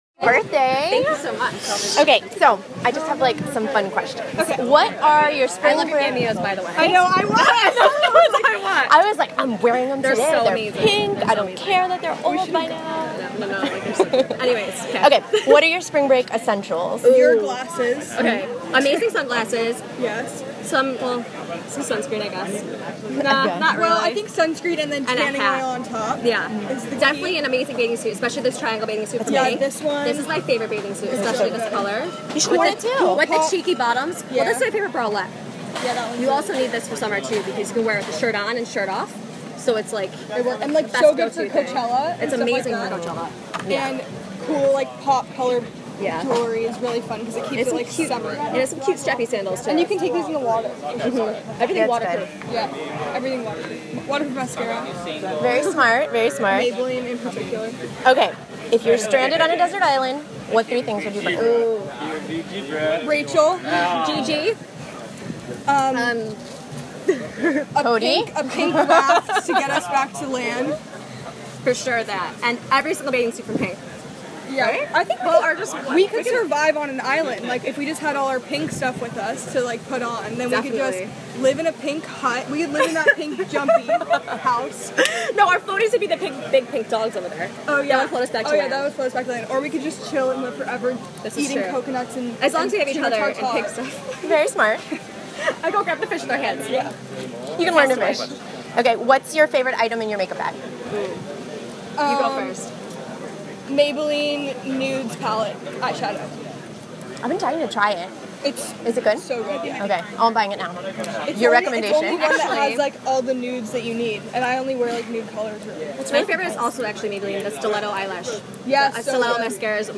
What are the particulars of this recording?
Victoria’s Secret PINK Nation Hosts The Ultimate Spring Break Bash at Surfcomber Miami, South Beach. The pool party was a total blast- super girly and pink (as expected!).